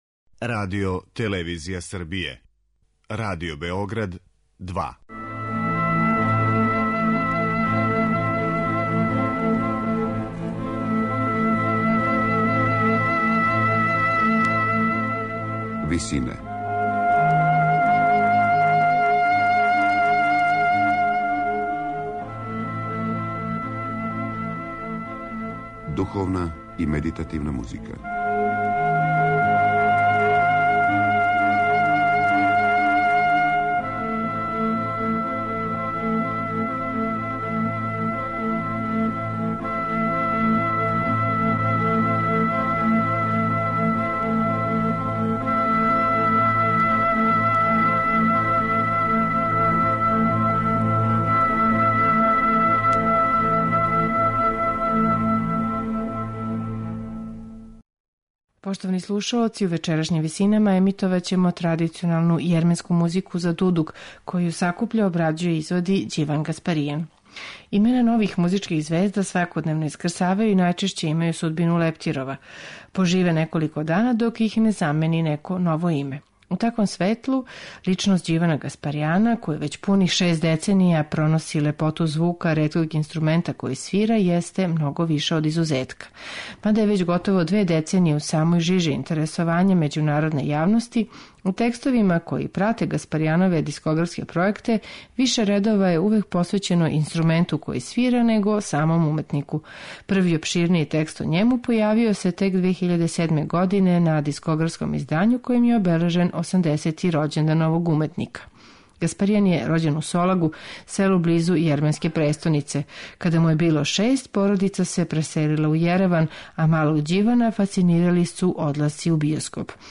У вечерашњим Висинама слушаћемо осам традиционалних јерменских мелодија за дудук и мали инструментални ансамбл, у извођењу Ђивана Гаспаријана и његовог ансамбла.